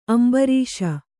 ♪ ambarīṣa